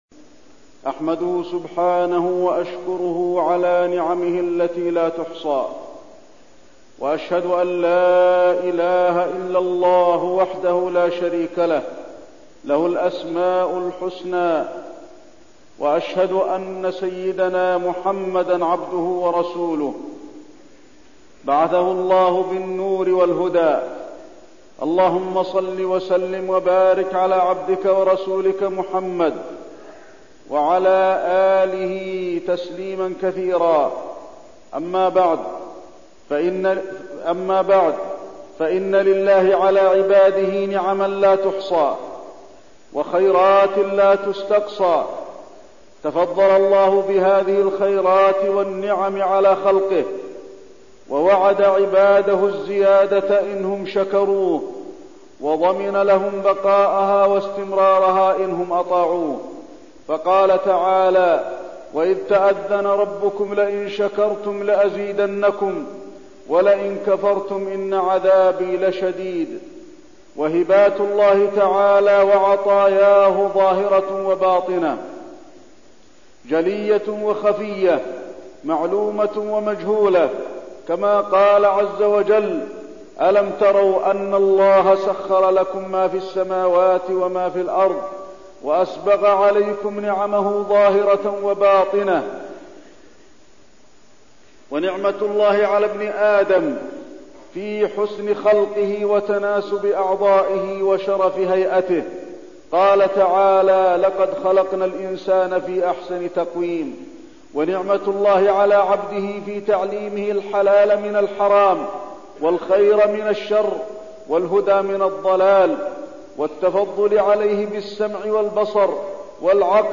تاريخ النشر ٢٠ جمادى الأولى ١٤١١ هـ المكان: المسجد النبوي الشيخ: فضيلة الشيخ د. علي بن عبدالرحمن الحذيفي فضيلة الشيخ د. علي بن عبدالرحمن الحذيفي النعم والشكر